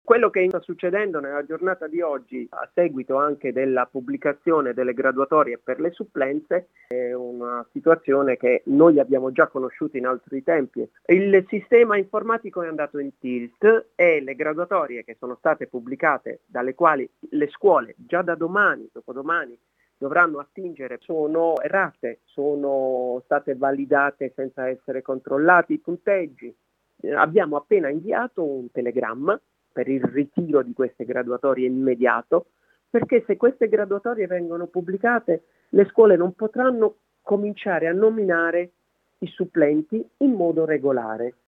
Stamattina abbiamo intervistato